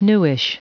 Prononciation du mot newish en anglais (fichier audio)
Prononciation du mot : newish